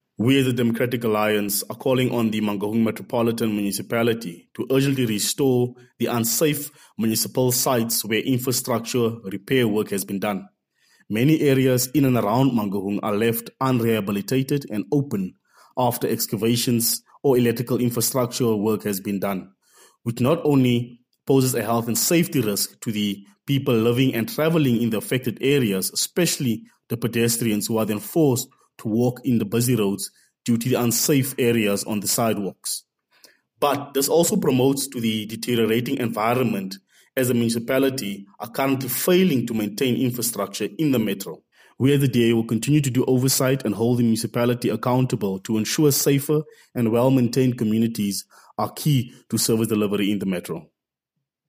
Afrikaans soundbites by Cllr Lyle Bouwer and